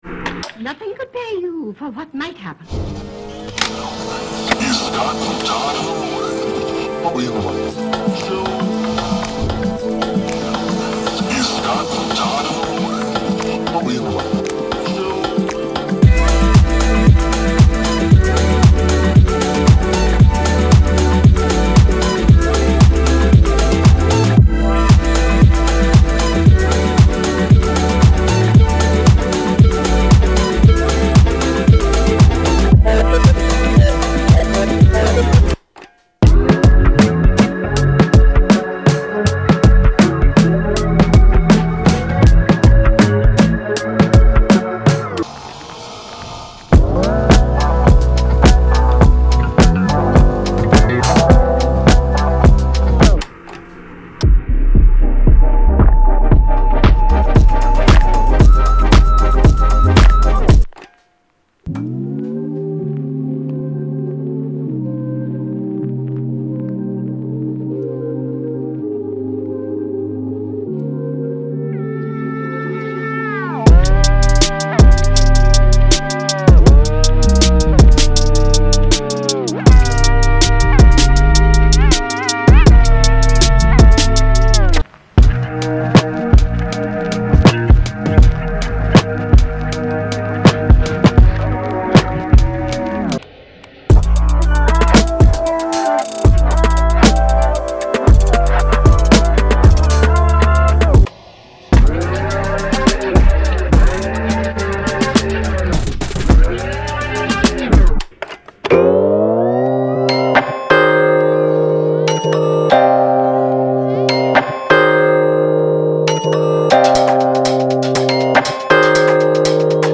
这是一个强大的多功能库，在整个中流派保持着凝聚力的声音美感，同时涵盖了中节奏，嘻哈，House，LoFi等多种流派。
提供120种详细的个人打击乐，为您制作的作品打下基础，这些乐曲包括活泼的军鼓，尘土飞扬的脚鼓，个性化的打击乐和波光粼粼的上衣。
潜入大量音乐作品中，聆听精心制作的配对，例如温暖的电贝司线路，膨胀的旋律旋律，清澈的打击垫等等。
您可以找到对过渡部分有帮助的元素，例如反向扫描，可能适合用作气氛以填充填充的纹理层，甚至是对话示例以使您的曲目具有某种叙事和身份。
• Tempos – 80, 105, 115, 120BPM